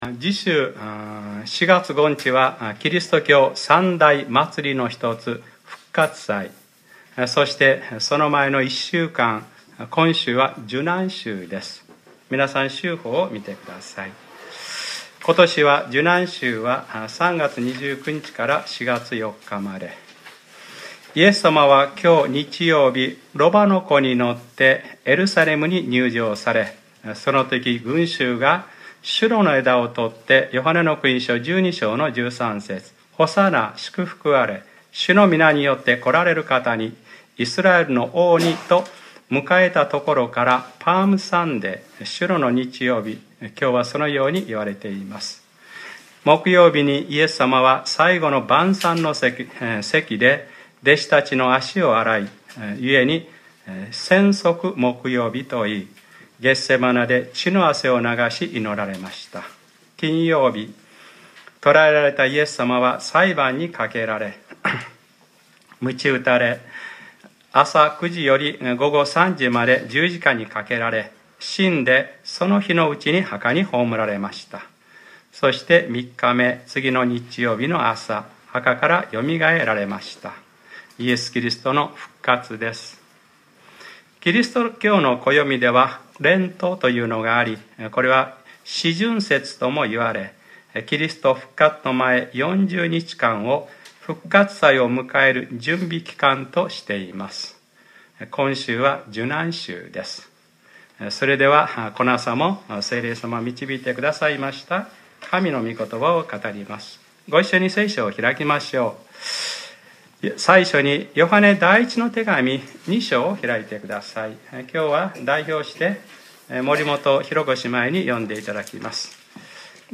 2015年03月29日）礼拝説教 『Ⅰヨハネｰ２：なだめの供え物』